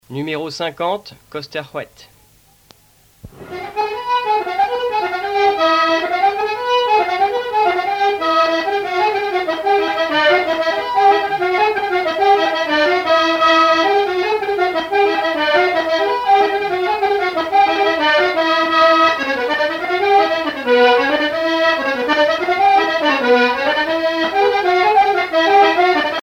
danse : kost ar c'hoad